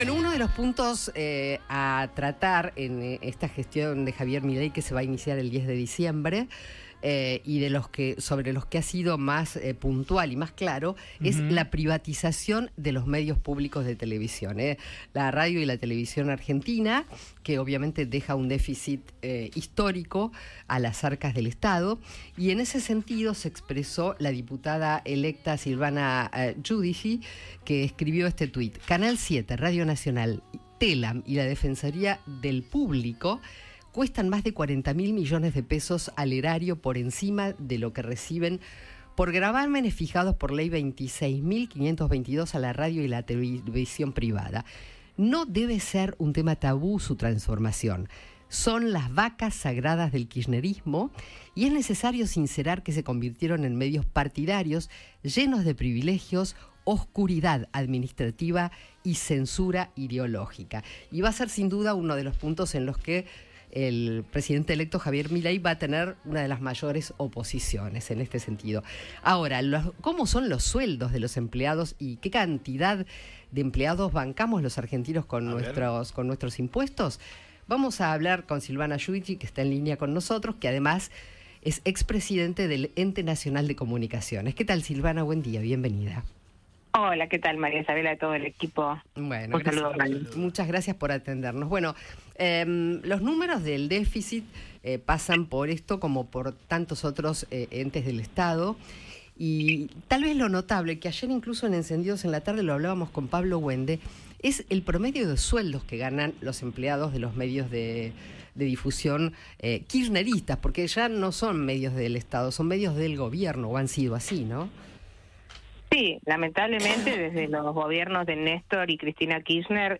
Silvana Giudici, ex presidente del ENACOM, dialogó con el equipo de Alguien Tiene que Decirlo sobre los proyectos de privatización de los medios públicos que planea Javier Milei.